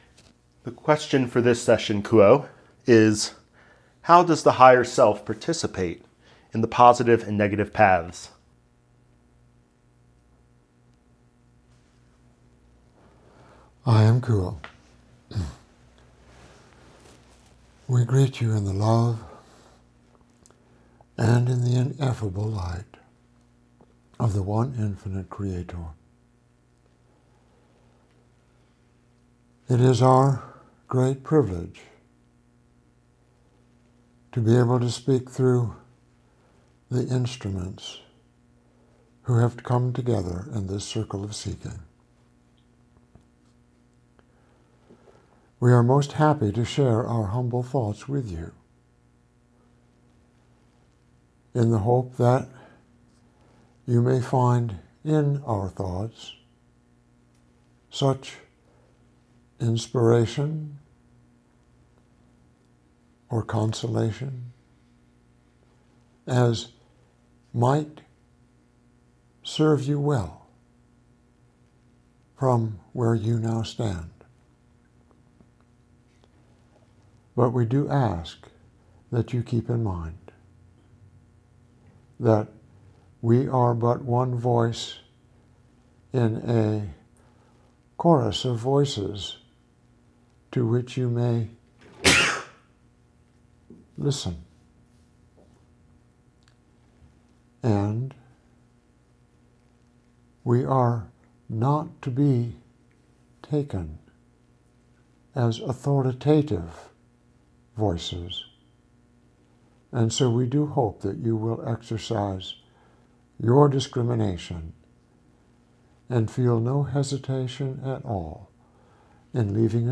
Inspirational messages from the Confederation of Planets in Service to the One Infinite Creator